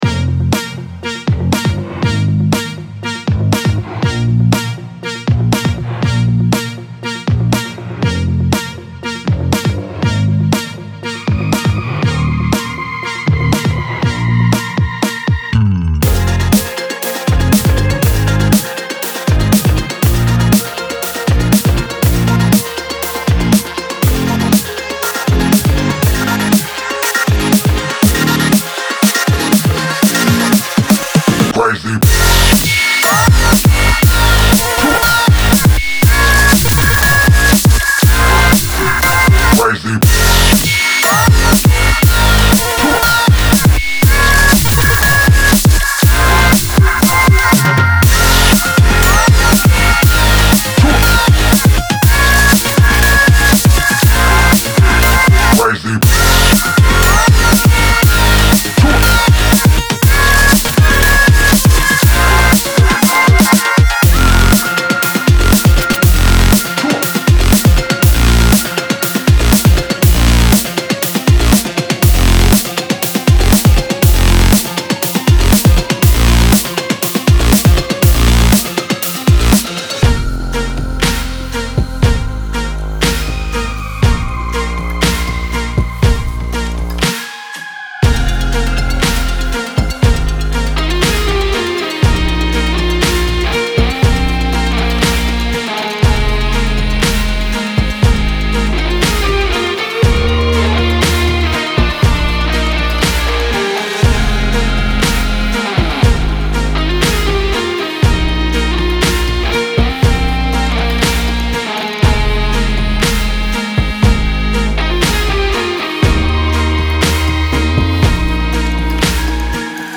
BPM120-120
Audio QualityPerfect (High Quality)
Dubstep song for StepMania, ITGmania, Project Outfox
Full Length Song (not arcade length cut)